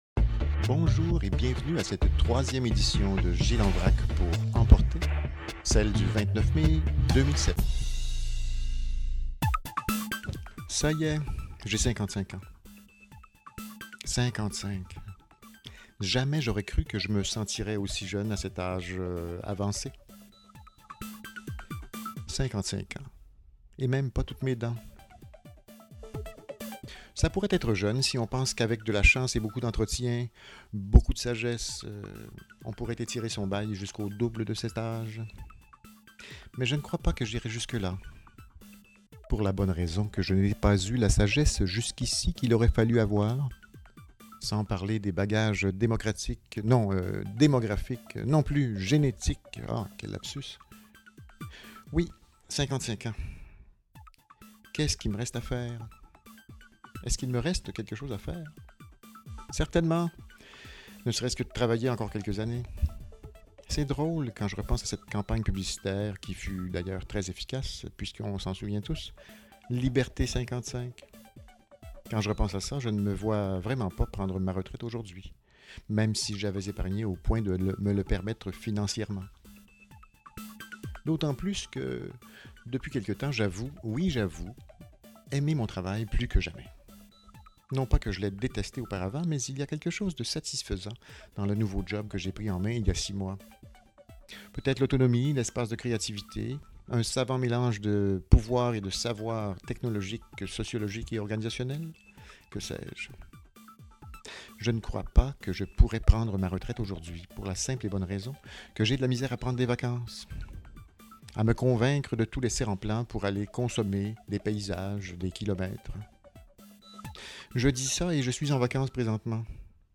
Il faut garder à l’esprit cette manière de diffuser lorsque vous écouterez les billets (un peu long) : ça ressemble plus à une courte émission de radio, abordant quelques sujets, reliés ou non entre eux, plutôt qu’à la lecture d’un court billet de mon Carnet.